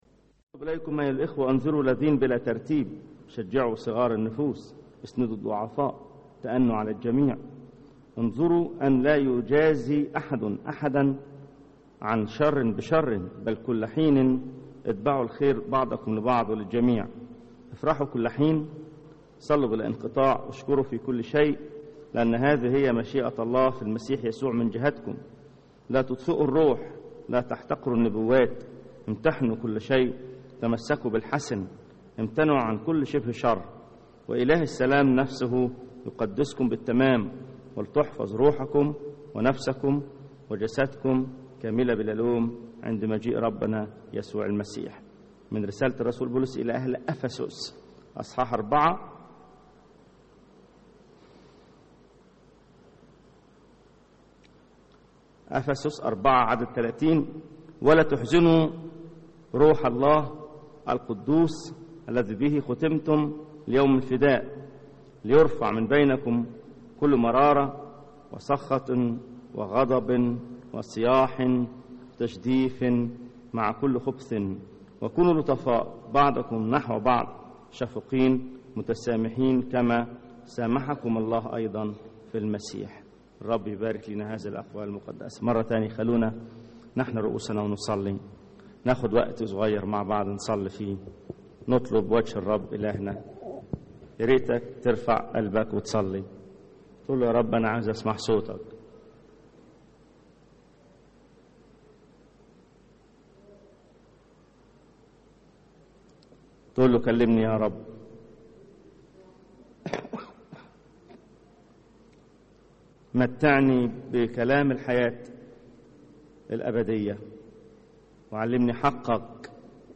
سلسلة من ثلاث عظات عن الروح القدس.. العظة الثالثة – الامتلاء بالروح القدس